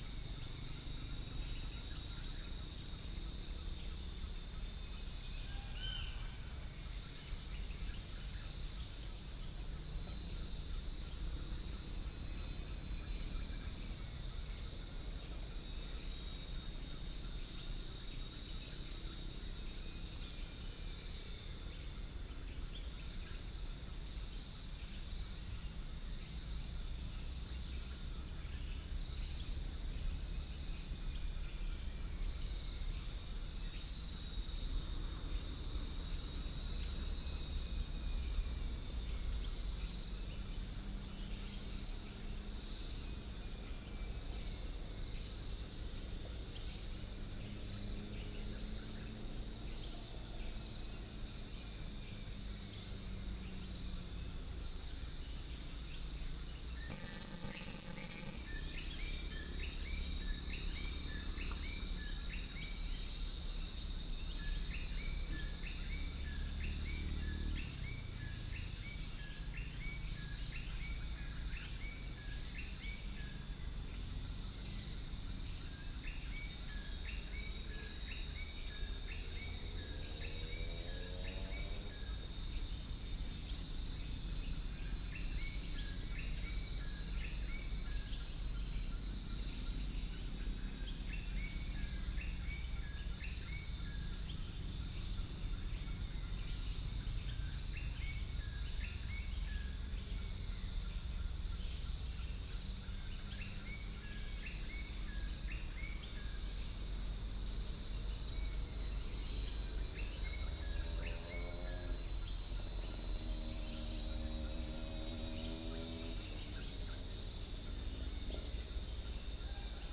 Upland plots dry season 2013
Cyanoderma erythropterum
Centropus sinensis
Picus puniceus
Malacopteron magnirostre
0 - unknown bird